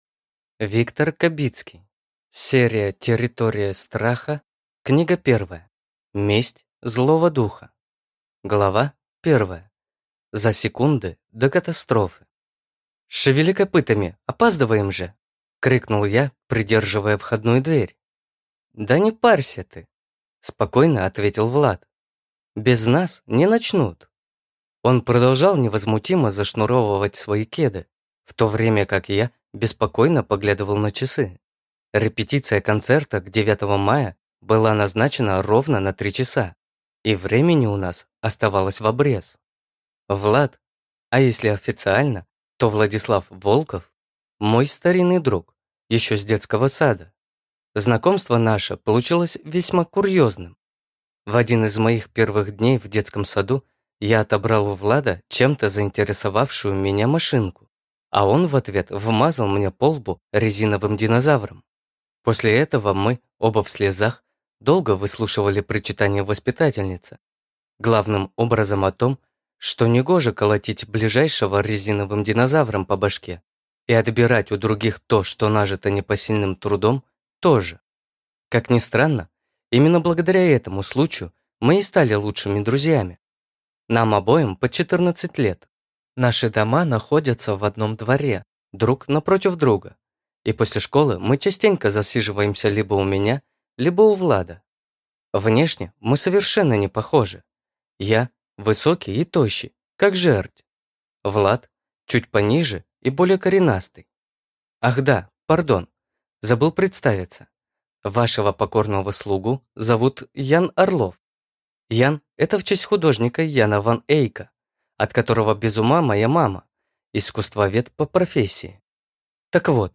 Аудиокнига Месть злого духа | Библиотека аудиокниг